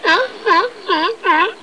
00584_Sound_phoque.mp3